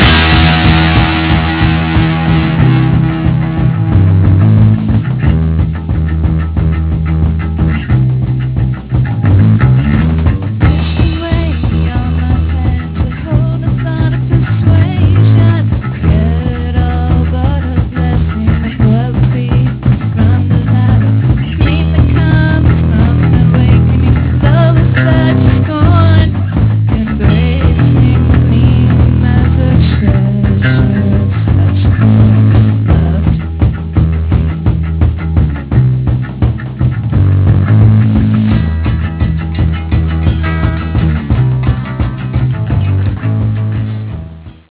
Vocals, Guitar, 12-String Guitar, Bass